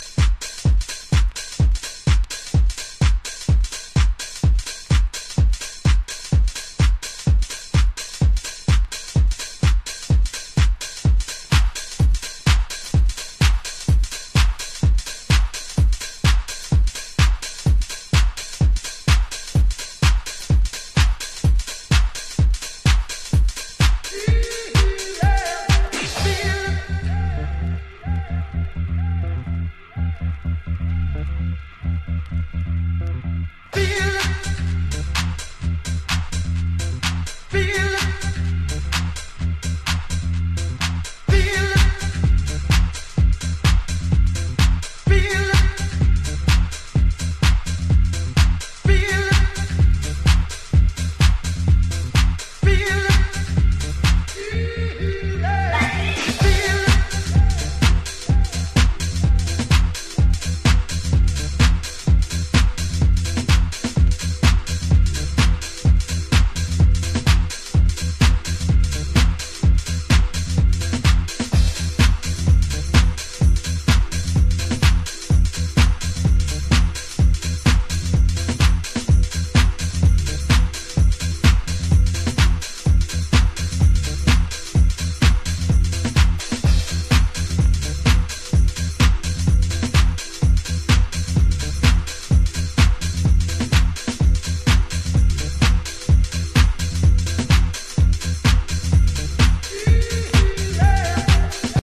わかっていても足がソワソワするビートに定番のヴォイスサンプルやアコースティックのウォーミー塩梅がバッチリです。